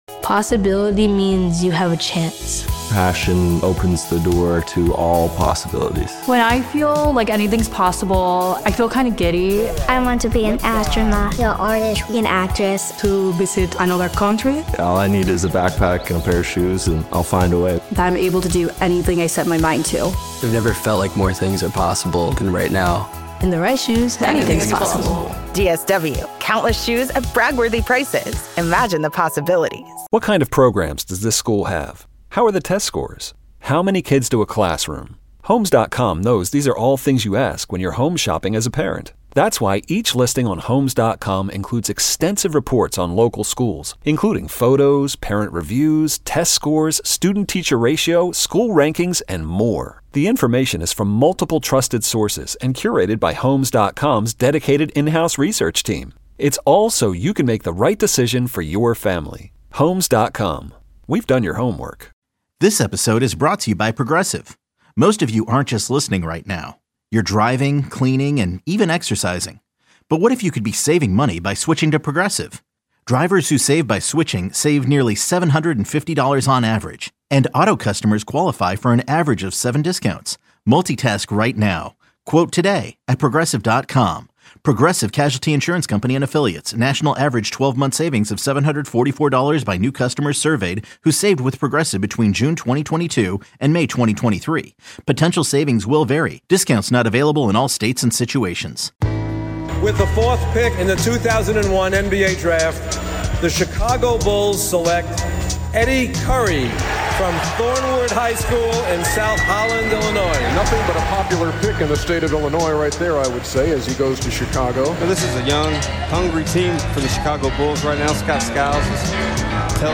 Chicago sports talk with great opinions, guests and fun.